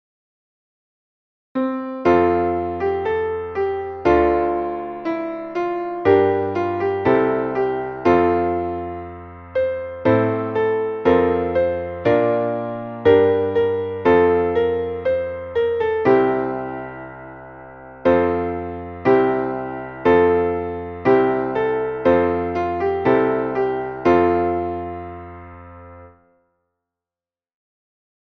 Traditionelles Weihnachtslied